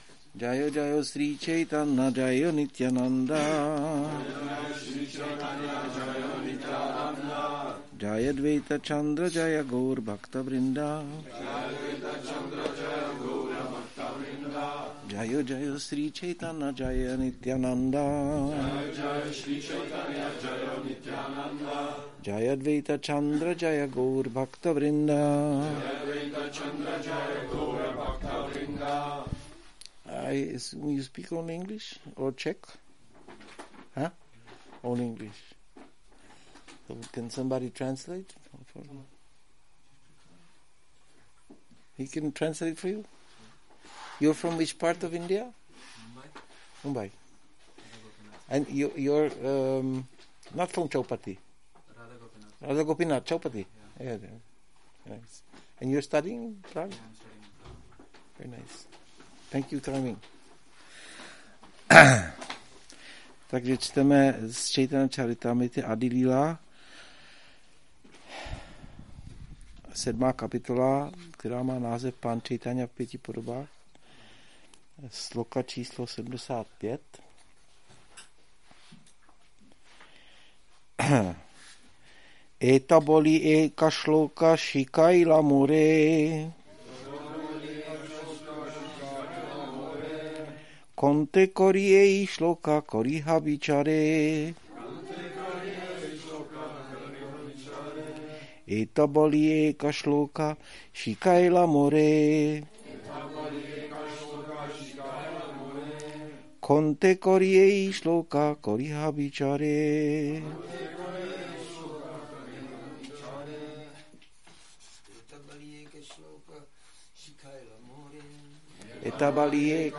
Přednáška CC-ADI-7.75